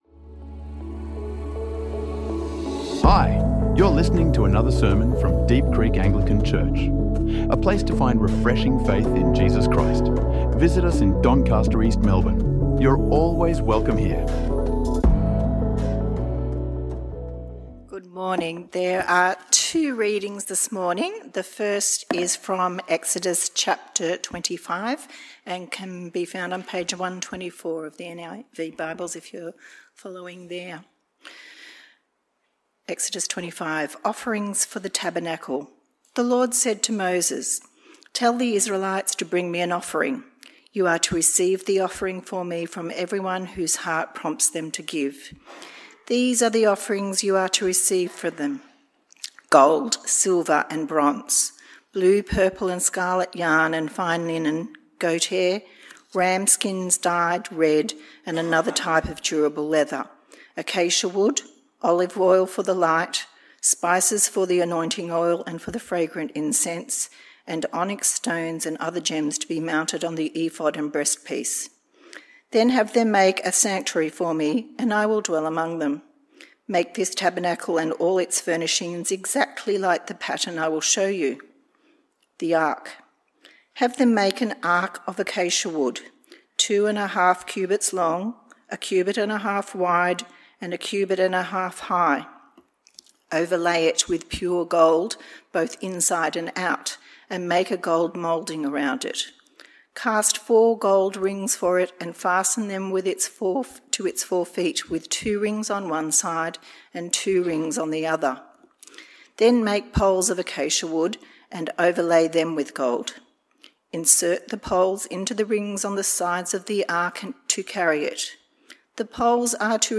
Living Before The Throne - Week 1 | Sermons | Deep Creek Anglican Church